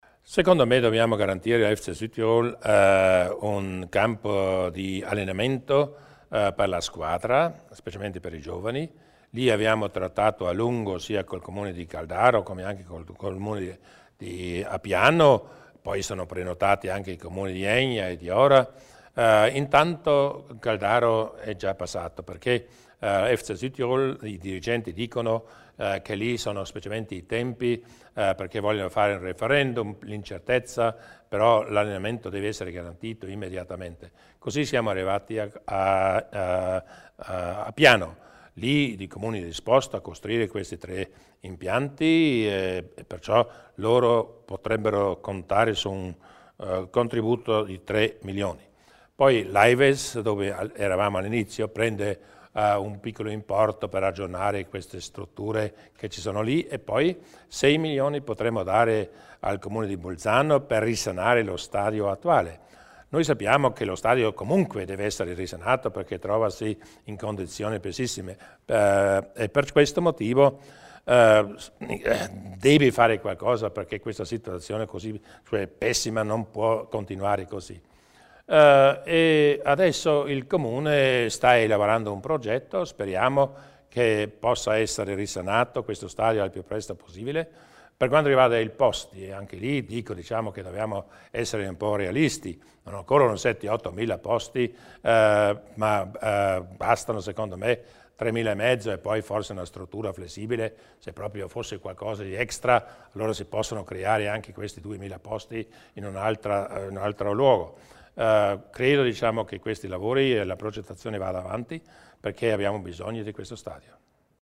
Il Presidente Durnwalder illustra i dettagli del futuro dello stadio Druso di Bolzano